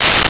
Tear Paper 04
Tear Paper 04.wav